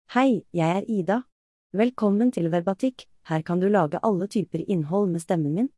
Ida — Female Norwegian AI voice
Ida is a female AI voice for Norwegian.
Voice sample
Female
Ida delivers clear pronunciation with authentic Norwegian intonation, making your content sound professionally produced.